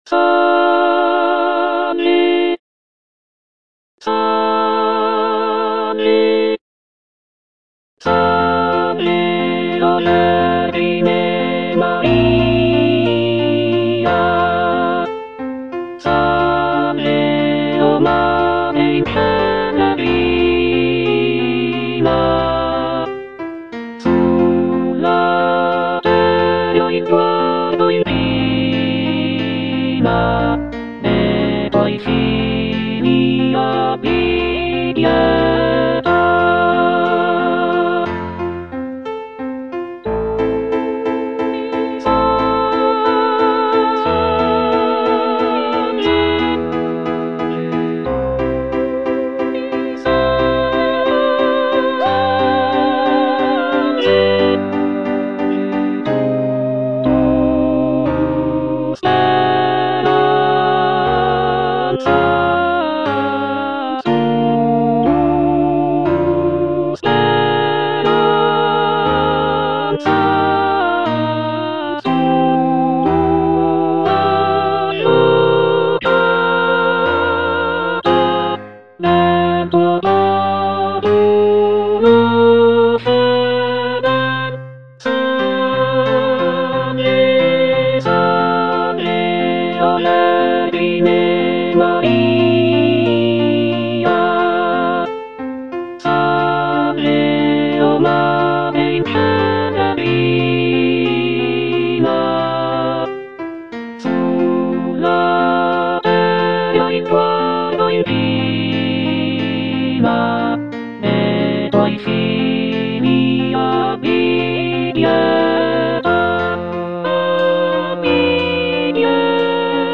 G. ROSSINI - SALVE O VERGINE MARIA (EDITION 2) Alto (Emphasised voice and other voices) Ads stop: auto-stop